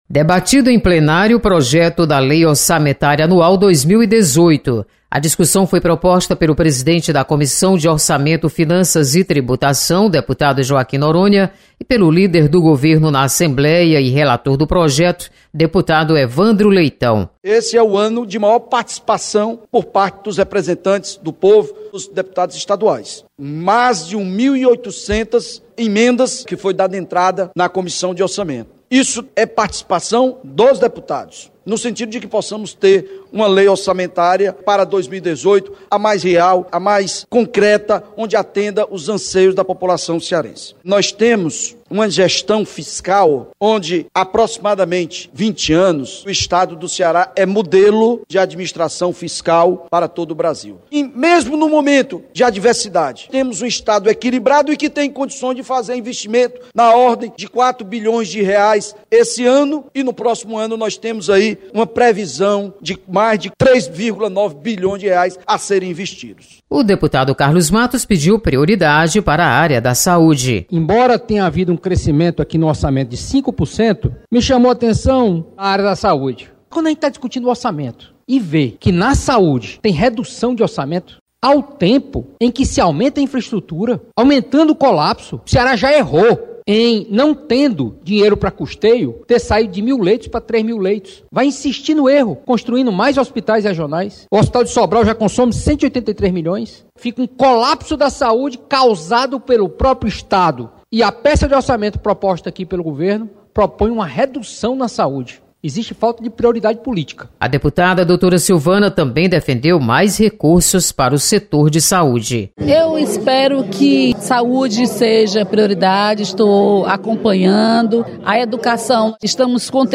Você está aqui: Início Comunicação Rádio FM Assembleia Notícias LOA